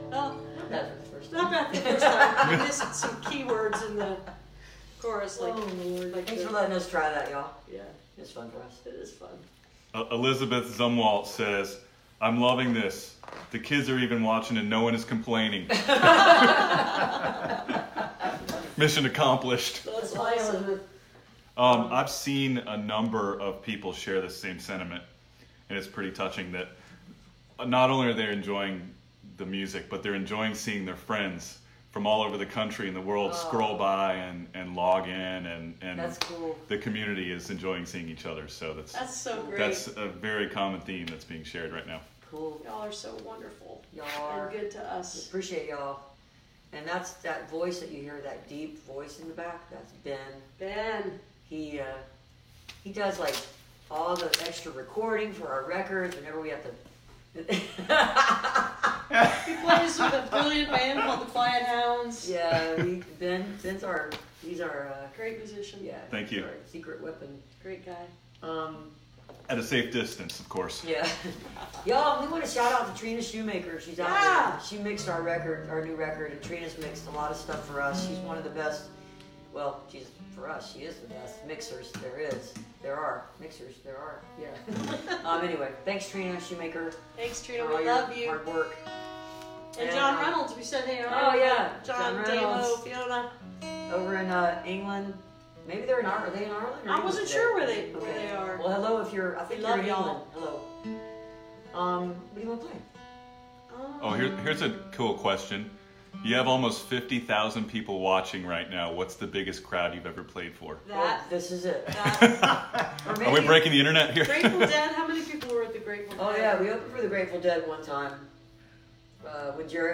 lifeblood: bootlegs: 2020-03-19: facebook live online show
22. talking with the crowd (6:11)